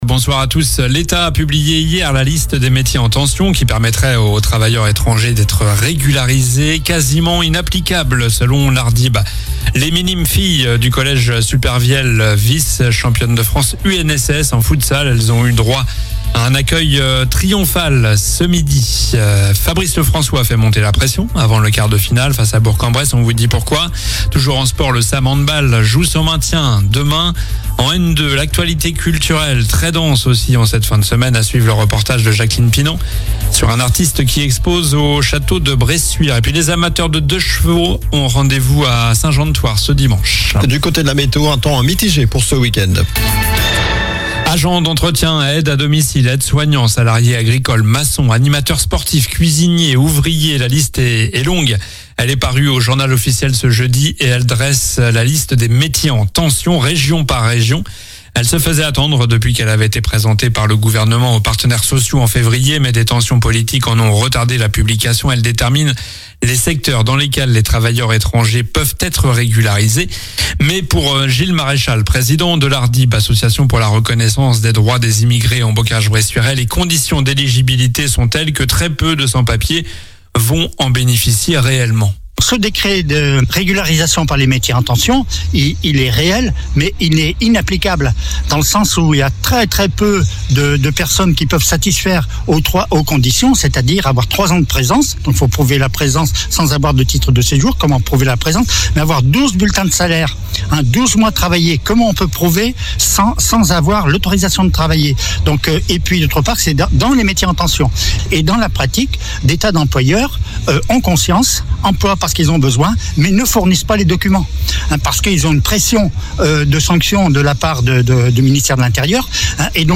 Journal du vendredi 23 mai (soir)